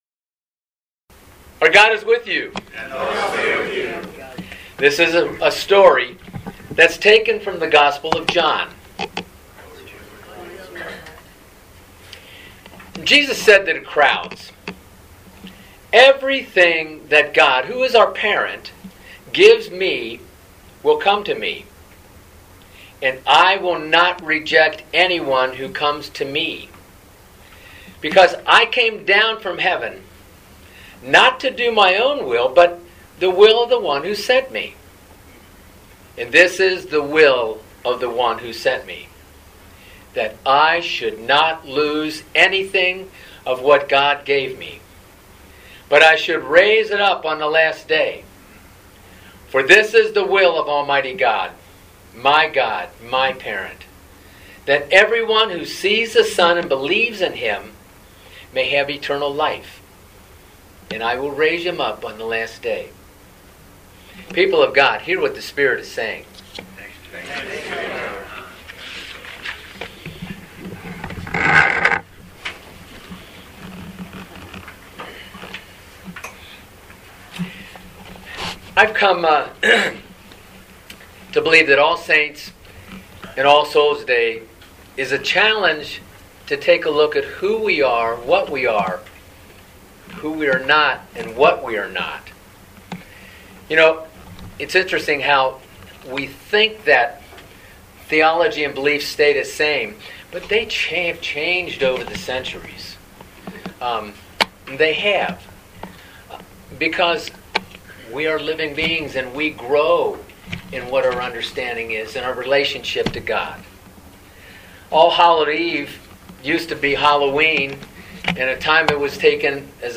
Living Beatitudes Community Homilies: Hey Saints!